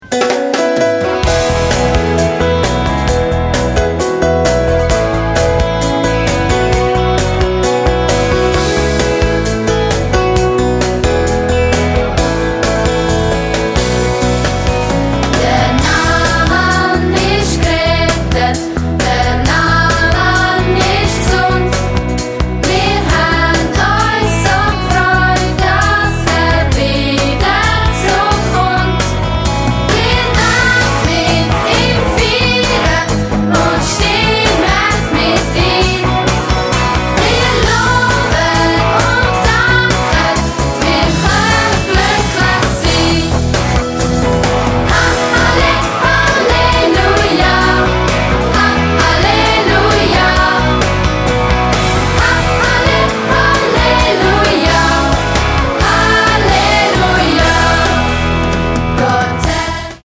Musical-CD mit Download-Code